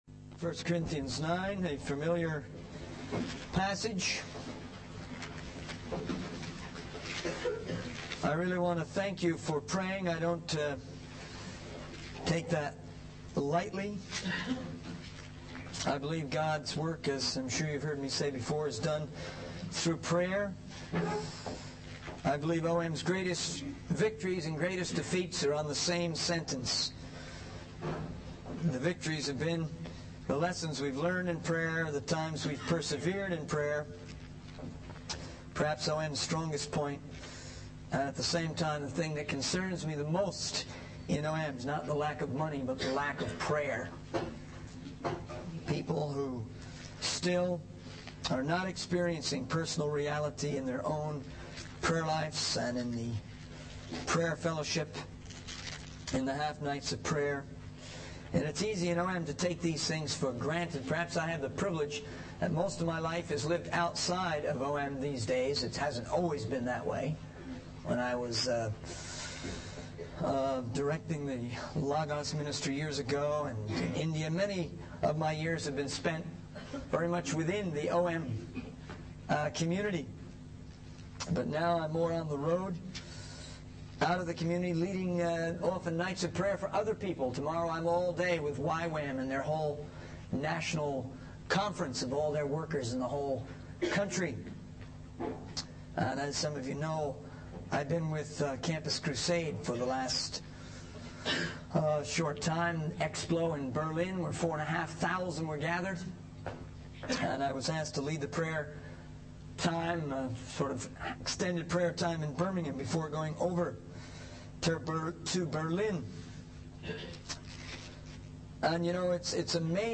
In this sermon, the speaker expresses his deep admiration for Eric Little, the Olympic athlete featured in the movie 'Chariots of Fire.'